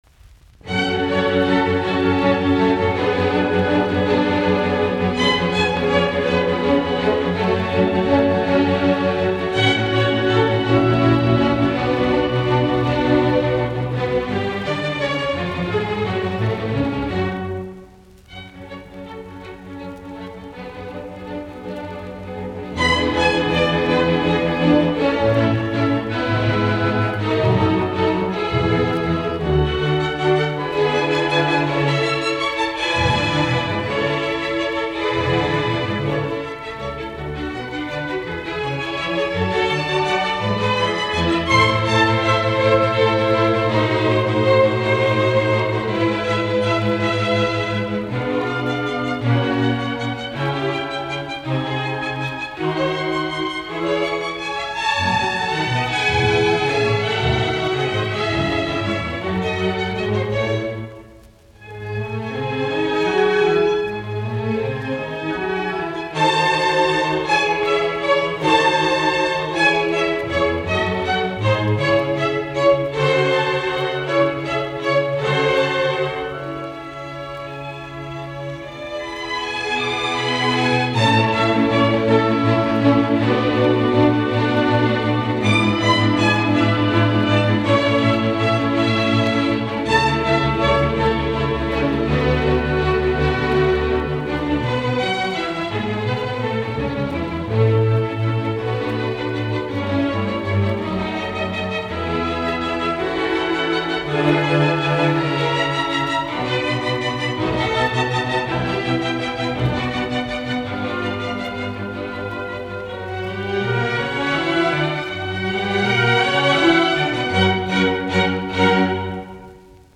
fis-molli
Allegro assai